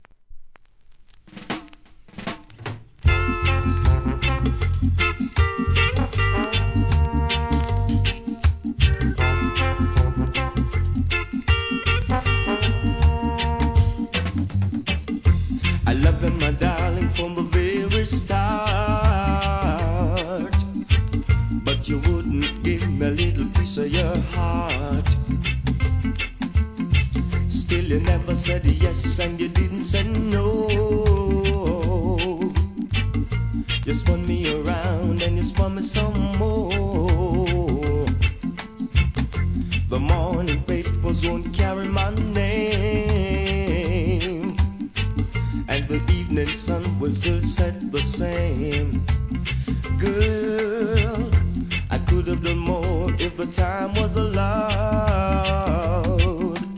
REGGAE
ソウル・フルなヴォーカルで聴かせるNICE ROCKSTEADY!!!